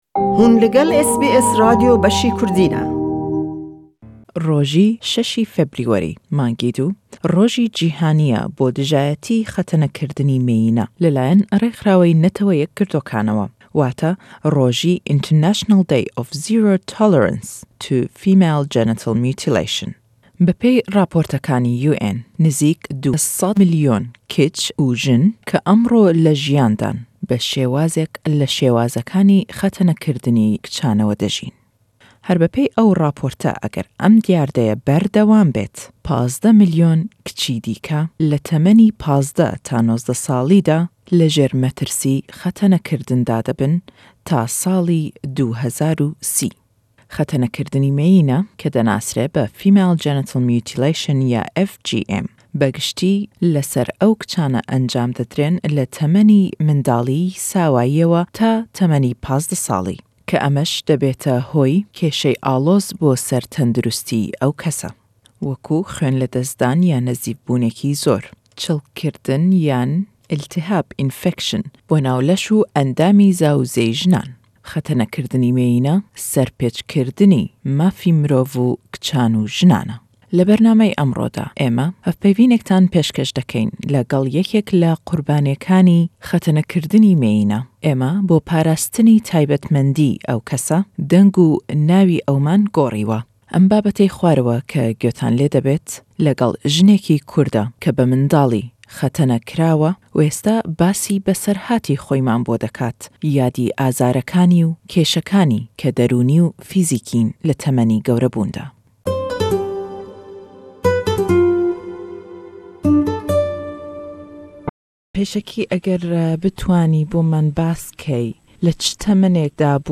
Rêkxirawey Tendurustî Cîhanî mezene dekat ke, zîyatir le 200 milyon jin xetene kirawin le cîhan. Em lêdwane le gell jinêkî Kurdî Australya ye, ke basî ew îşkenceyeman bo dekat û kardanewey le temenî gewrebûn da.